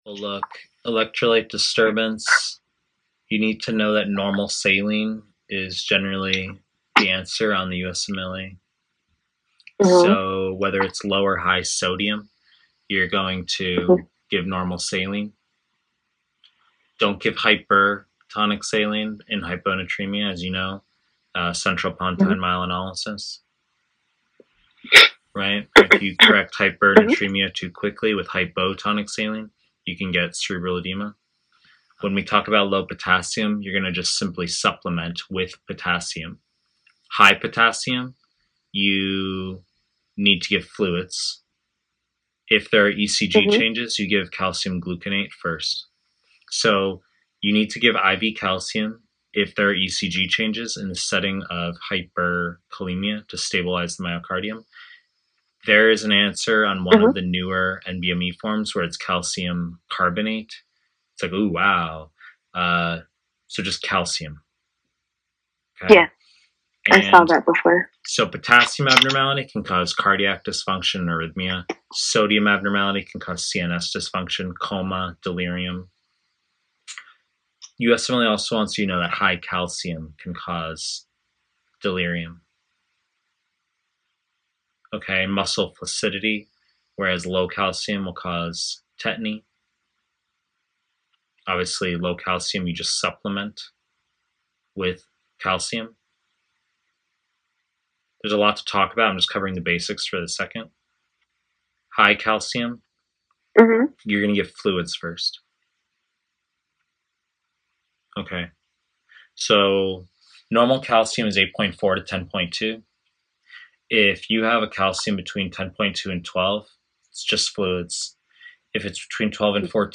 Pre-recorded lectures / Surgery